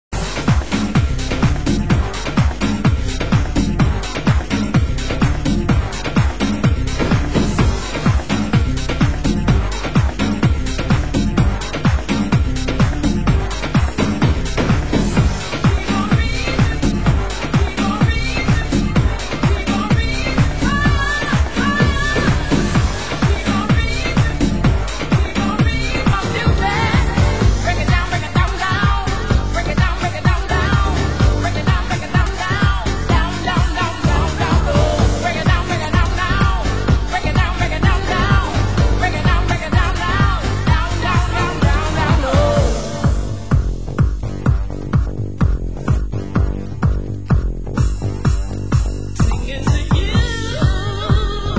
Tribal Anthem Vocal